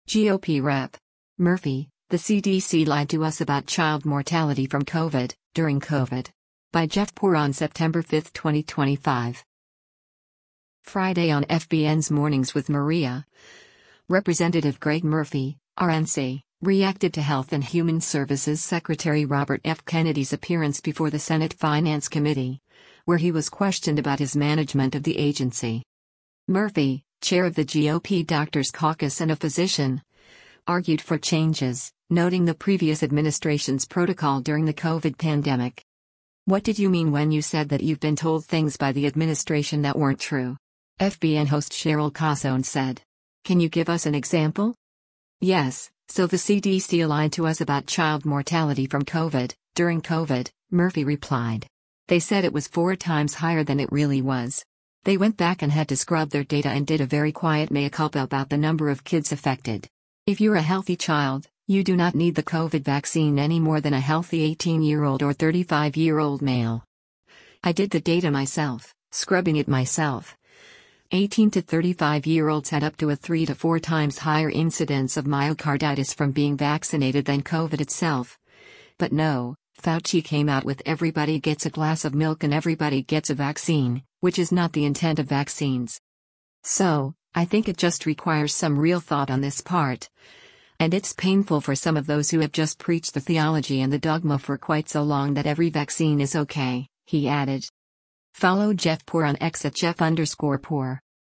Friday on FBN’s “Mornings with Maria,” Rep. Greg Murphy (R-NC) reacted to Health and Human Services Secretary Robert F. Kennedy’s appearance before the Senate Finance Committee, where he was questioned about his management of the agency.